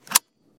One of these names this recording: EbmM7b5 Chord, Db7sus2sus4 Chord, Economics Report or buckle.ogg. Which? buckle.ogg